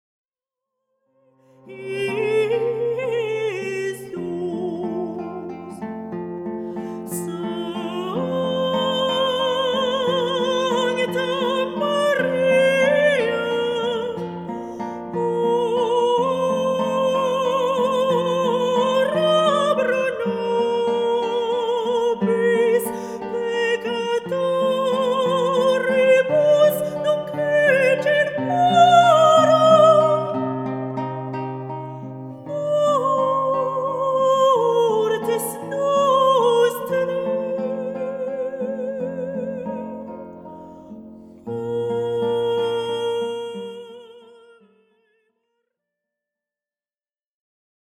Chants sacrés
La rencontre de la guitare et de la voix.
accompagnées à la guitare